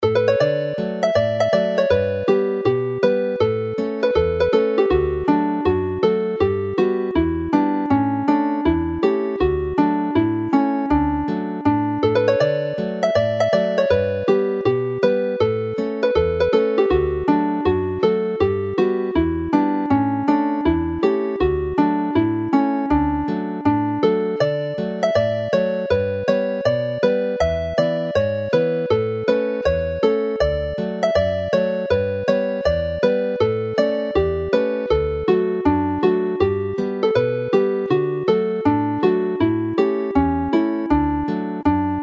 The sound files for the two tunes run as hornpipes and the score illustrations shown below the scores of the tunes give a fairly accurate portrayal of this.
This is how it is played as a hornpipe.
Note how connecting notes across boundaries gives the lilt to the tune.